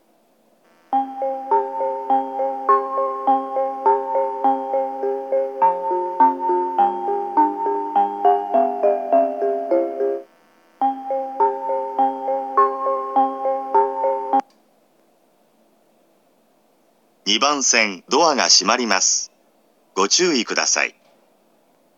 白岡駅　Shiraoka Station ◆スピーカー：ユニペックス小型
2番線発車メロディー